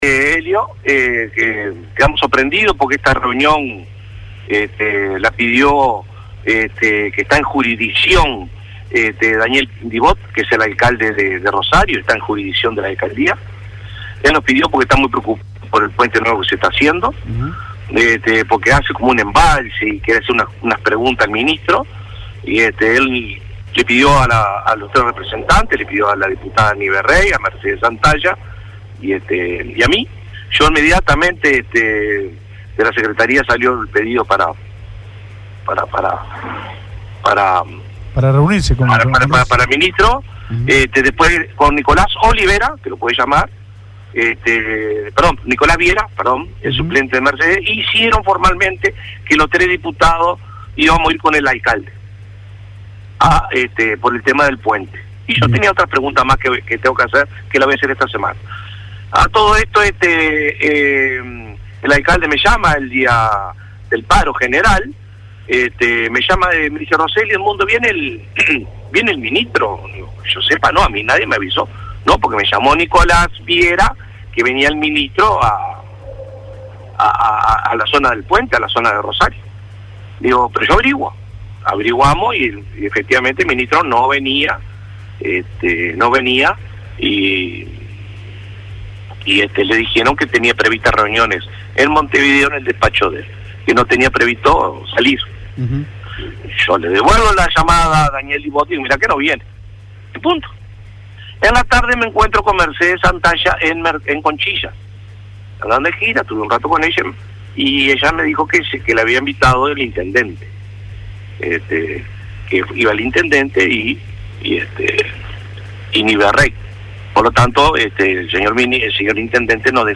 Roselli en el programa de este lunes TQH de Radiolugares explicó que esa reunión la había solicitado el alcalde de Rosario Daniel Dibot.
nota-edmundo-roselli.mp3